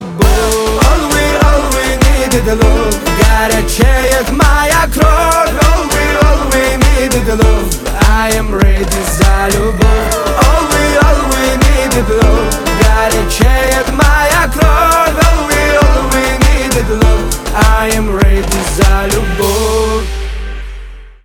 кавказские
битовые , гитара